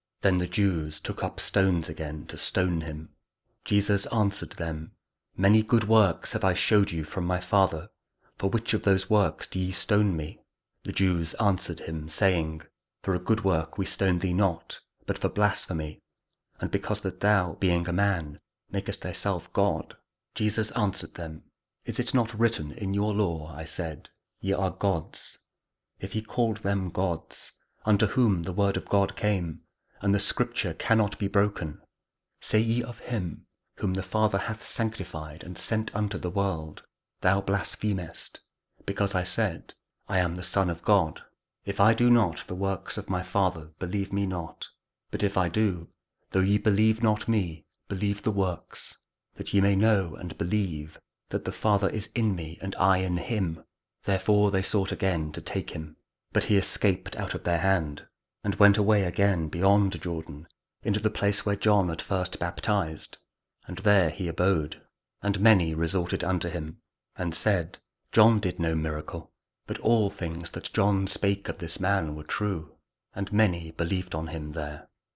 John 10: 31-42 – Lent Week 5, Friday (Audio Bible King James Version, KJV, Spoken Word)